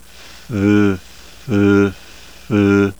1.2 Alternating voiceless and voiced sounds.
voicing.aiff